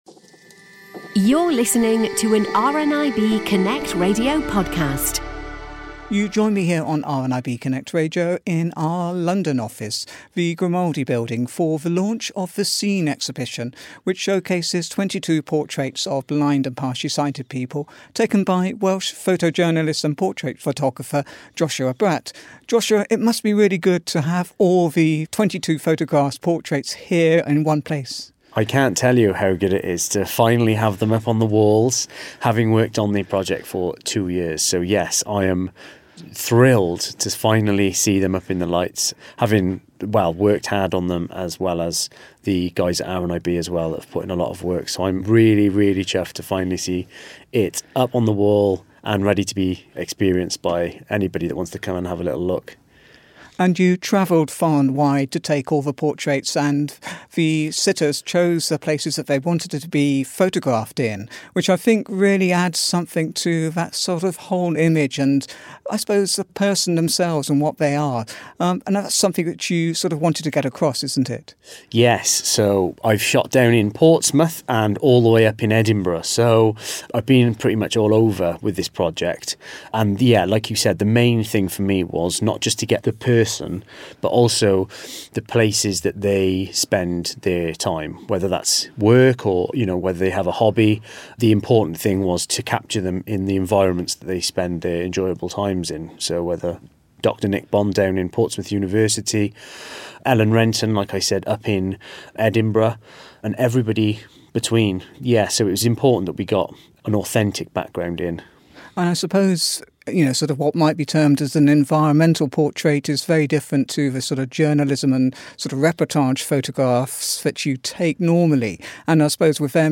At Seen Exhibition 1 - Interview